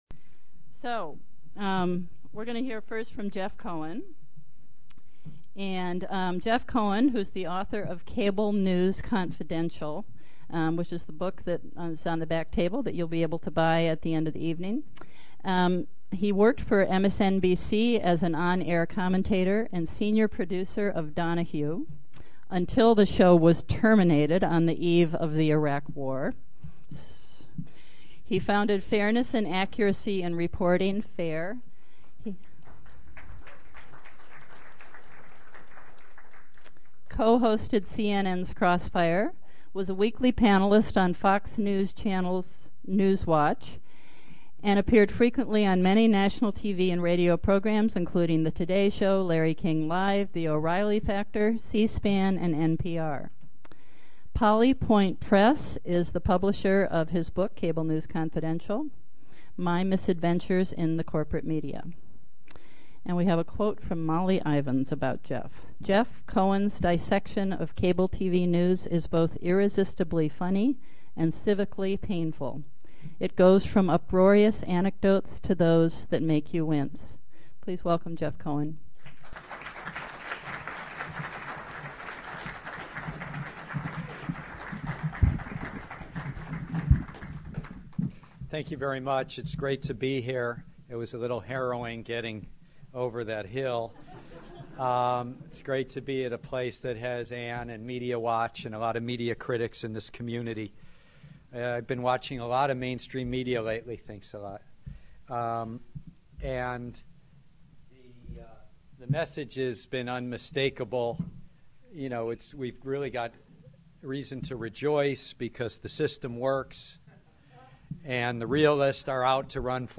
SCOTT RITTER and JEFF COHEN spoke on Dec. 9 about the war and the media's role in creating it.
AUDIO: Scott Ritter and Jeff Cohen at Louden Nelson on 12-9-06.